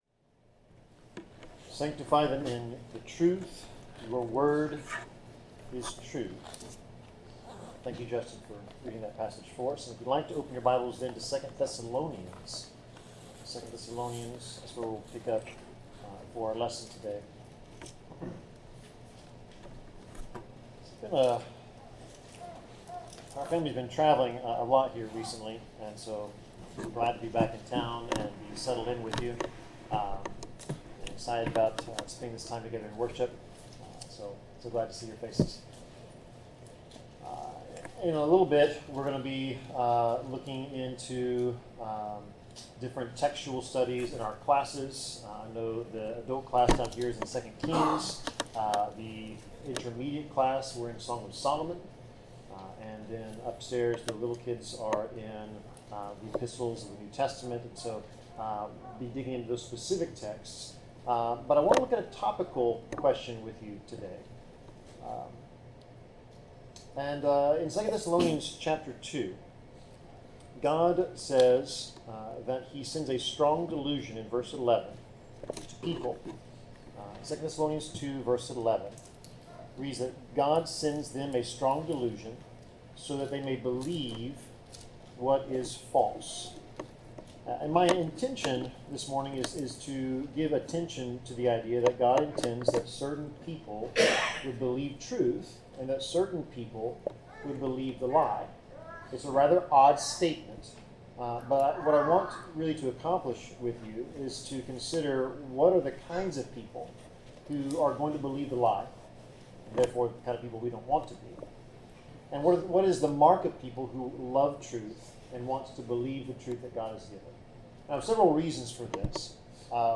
Passage: 2 Thessalonians 2:1-12 Service Type: Sermon